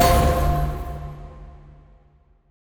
Shield Pickup.wav